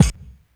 kick03.wav